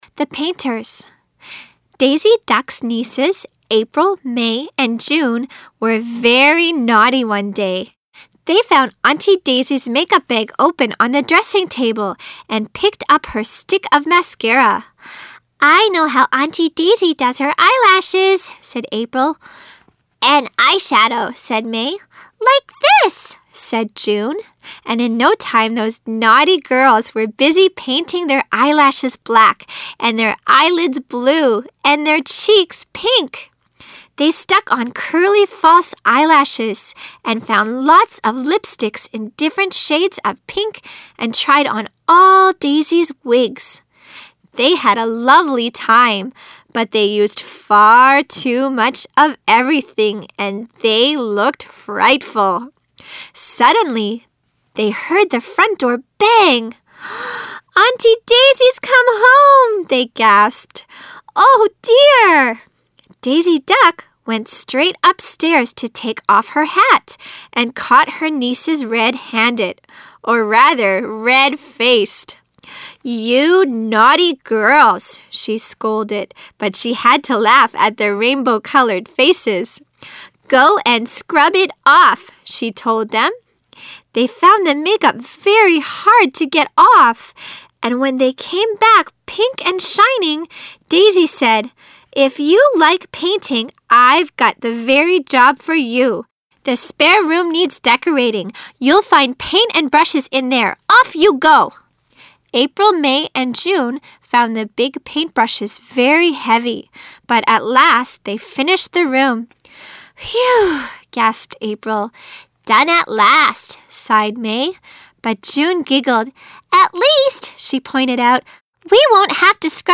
The Painters女聲版 （最近一週新上傳檔案）
第三篇-The Painters女聲版.mp3